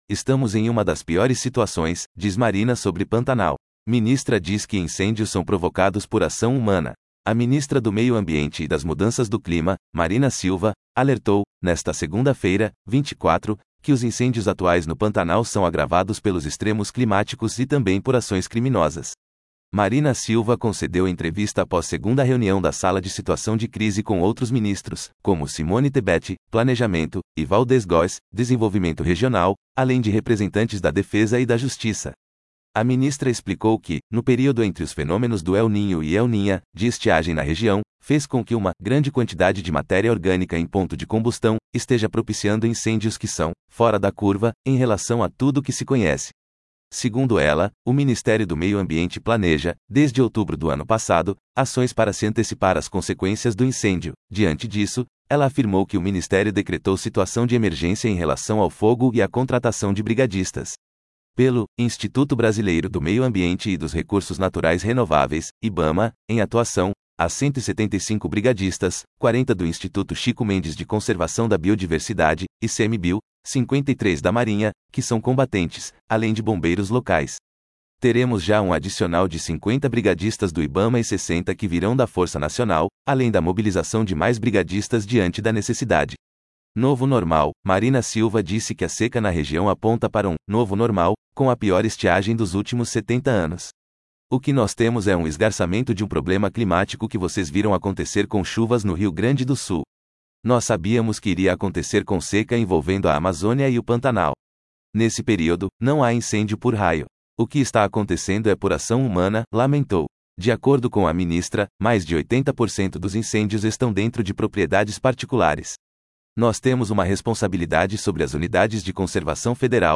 Marina Silva concedeu entrevista após segunda reunião da sala de situação de crise com outros ministros, como Simone Tebet (Planejamento) e Waldez Góes (Desenvolvimento Regional), além de representantes da Defesa e da Justiça.